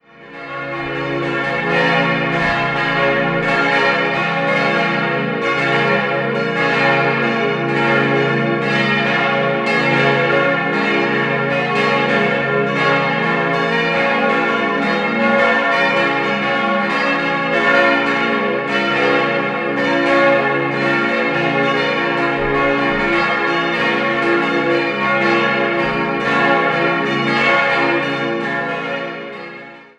5-stimmiges erweitertes Salve-Regina-Geläut: des'-f'-as'-b'-des'' Die Glocken wurden 1972 von Rudolf Perner in Passau gegossen, nachdem das alte Geläut beim Turmbrand 1971 zerstört wurde.